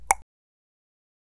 claquement-1.wav